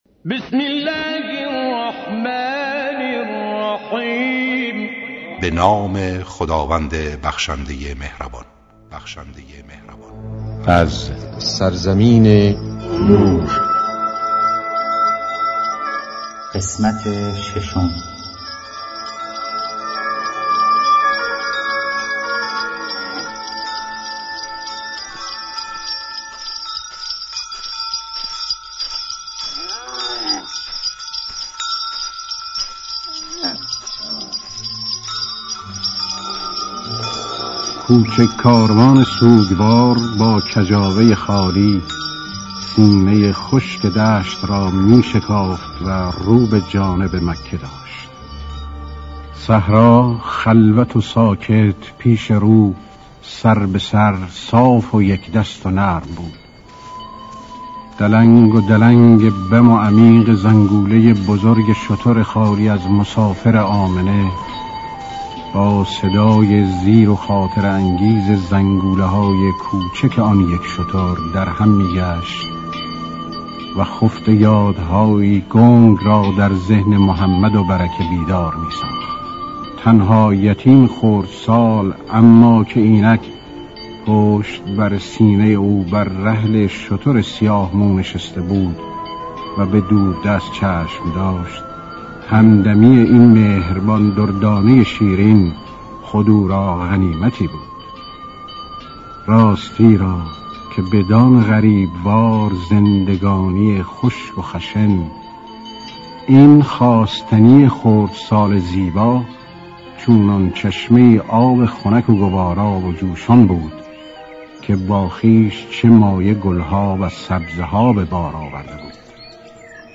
تمام قسمت‌های داستان شنیدنی زندگی پیامبر اسلام (ص) و امام علی (ع)؛ با اجرای مشهورترین صداپیشگان، با اصلاح و صداگذاری جدید
کتاب گویا